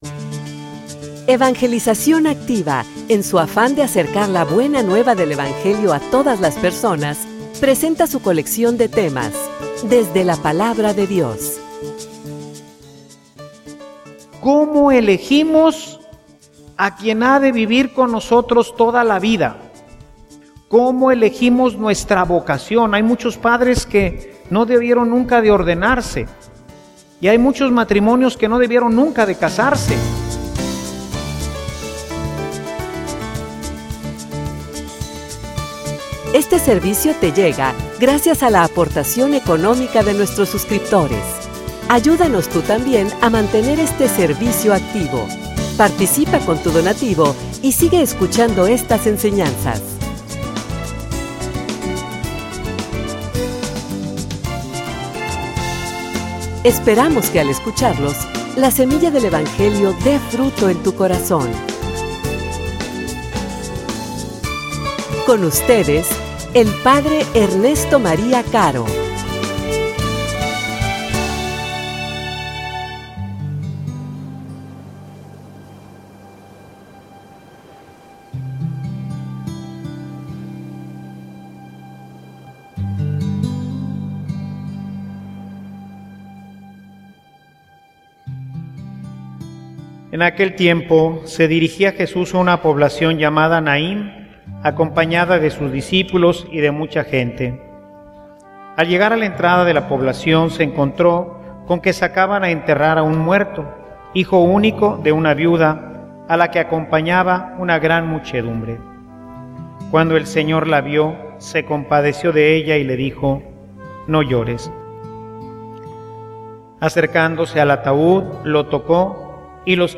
homilia_Un_Dios_de_misericordia.mp3